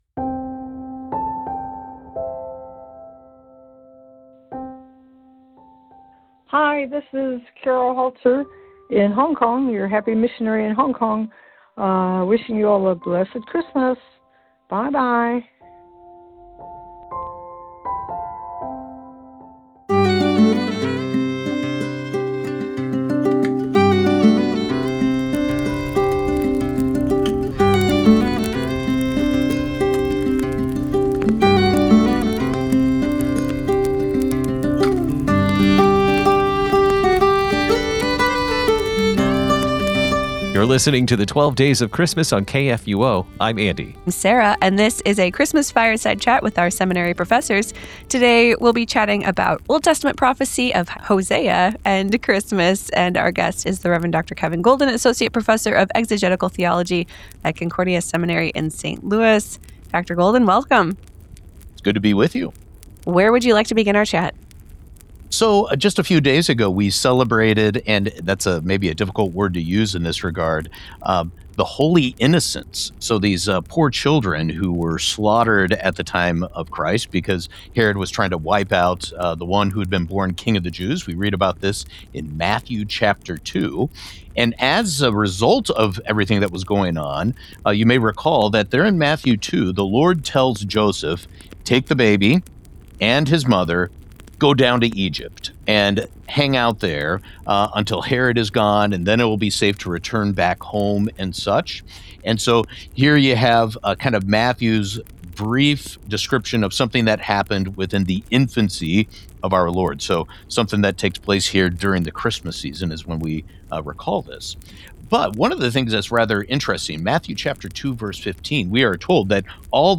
Fireside Chat